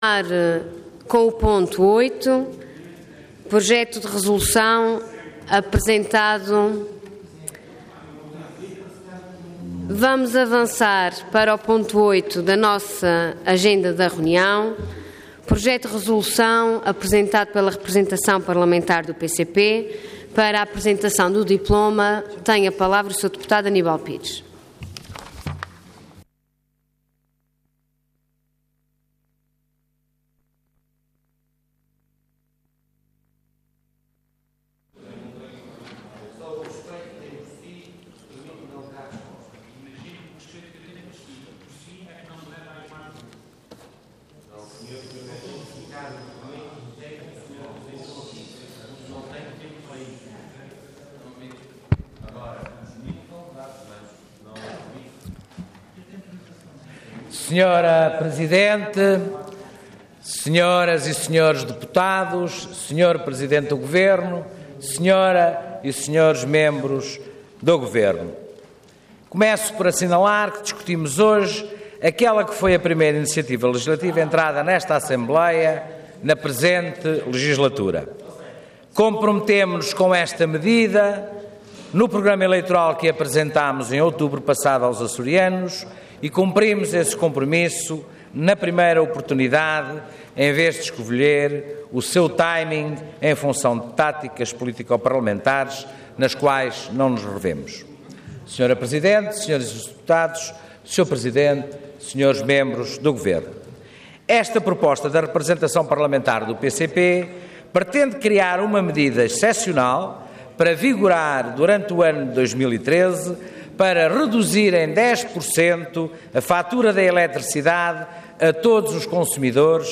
Intervenção Projeto de Resolução Orador Aníbal Pires Cargo Deputado Entidade PCP